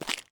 Footstep_Dirt_05.wav